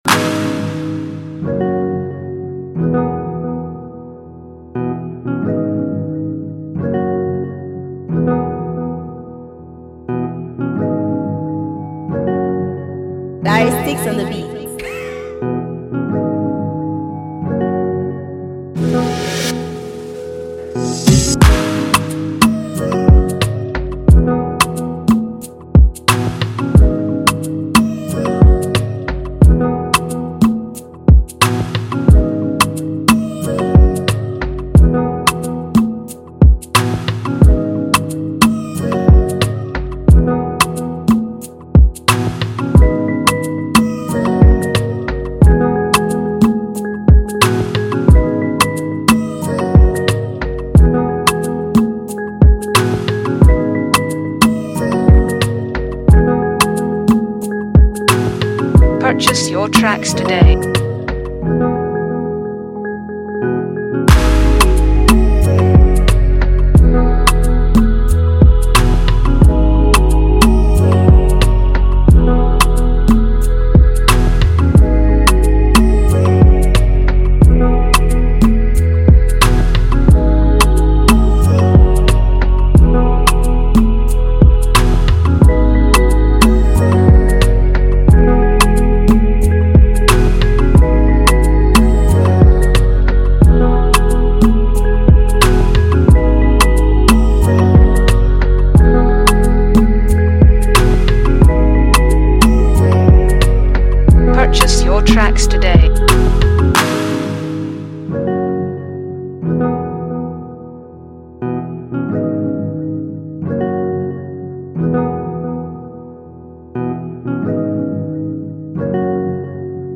Emotional type beat